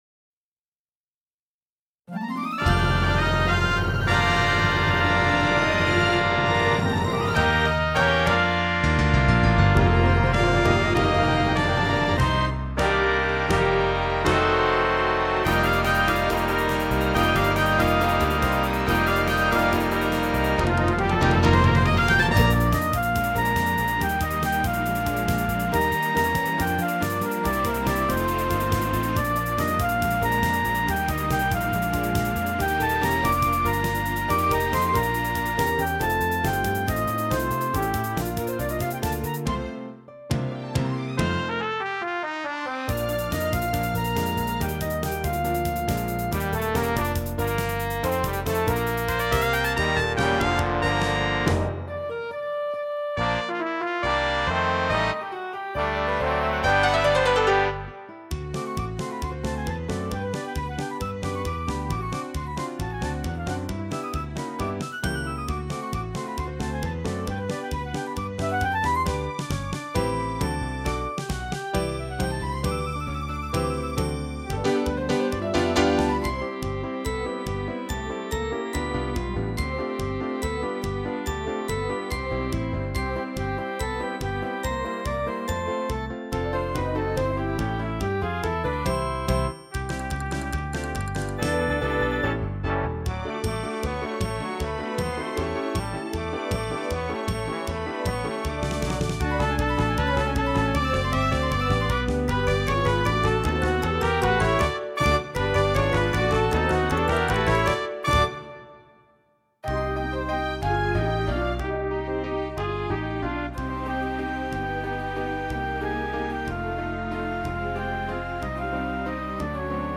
Choir Club
Choir Concert with the White Cliffs Symphonic Wind Orchestra
Our school choir of 50 pupils from Years 4, 5 and 6 will be singing 4 special songs accompanied by the orchestra.